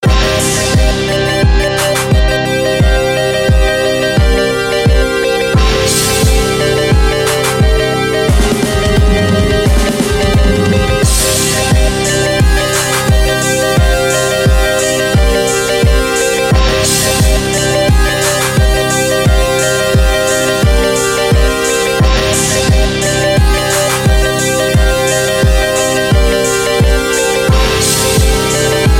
• Качество: 128, Stereo
боевая тема
Классная 8-ми битная мелодия для звонка!